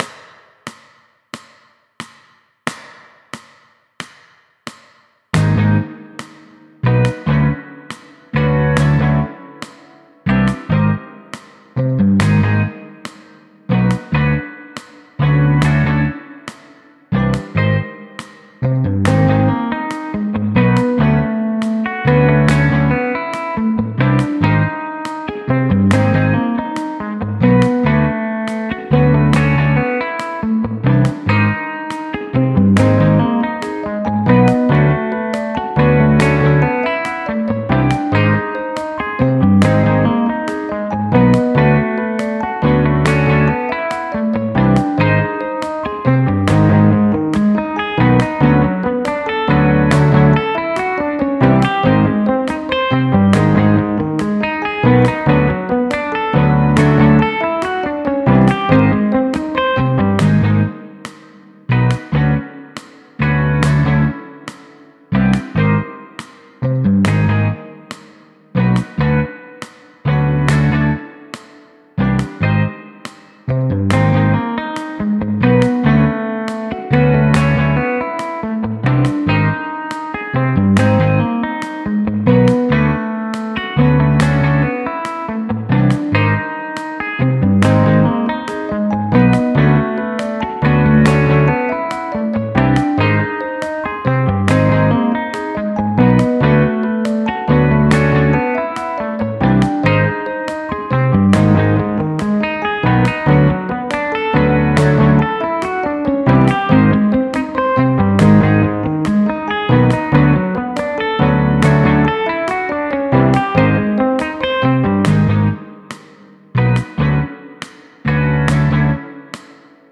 70 BPM with click